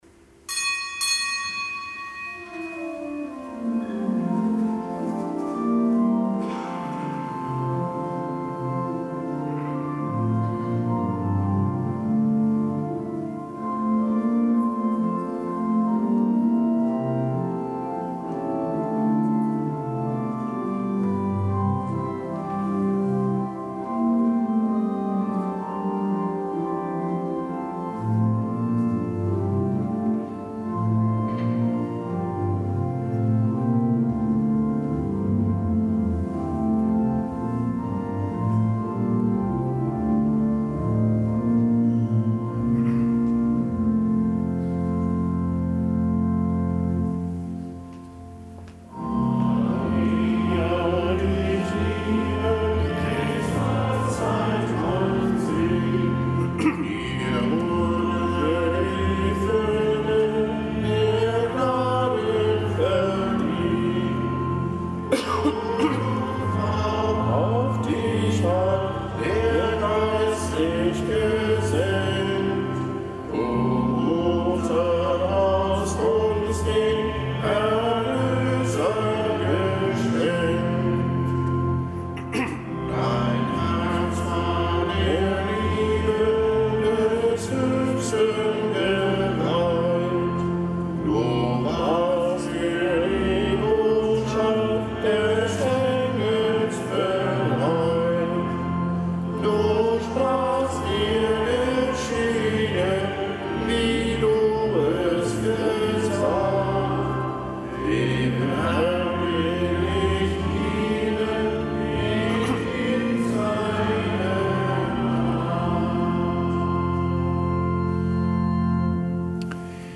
Kapitelsmesse am Samstag der zweiten Adventswoche
Zelebrant: Weihbischof Dominikus Schwaderlapp.